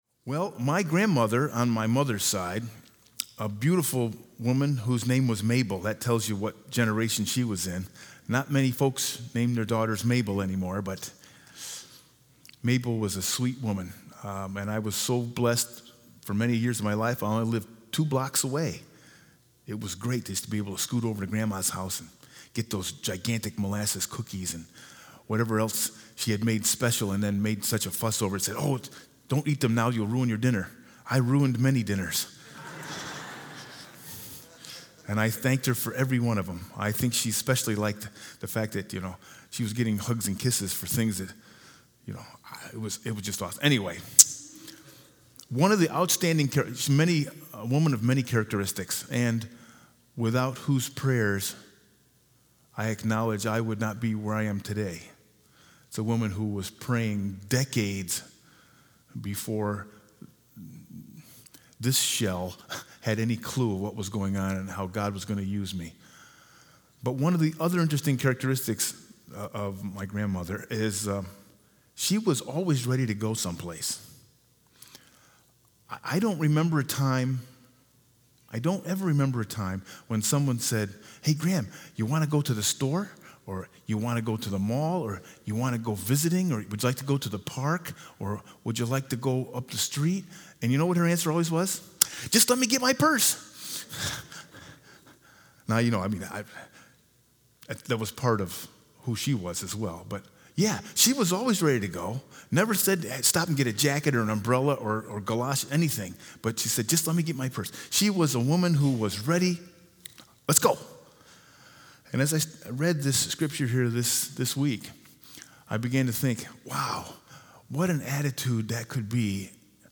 Sermon 8/11/19